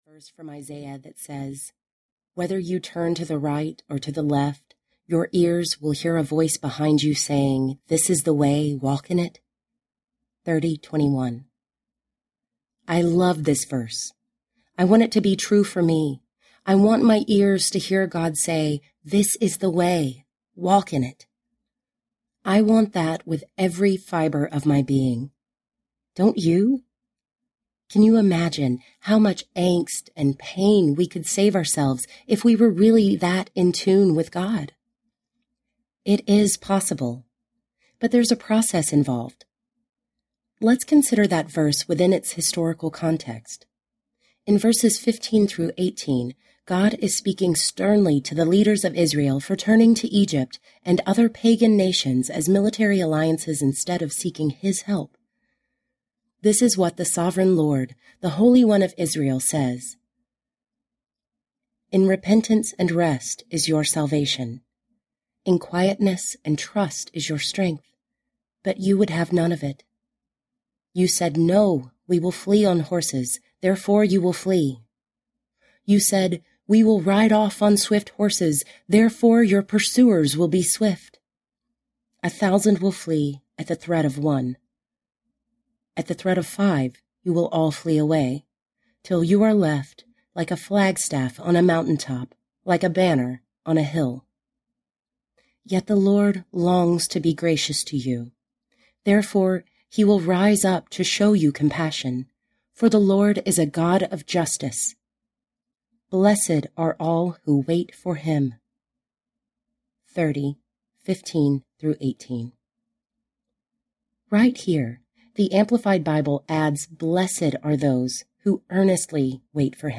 The Best Yes Audiobook
6.53 Hrs. – Unabridged